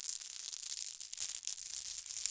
CHAIN_Pull_01_loop_mono.wav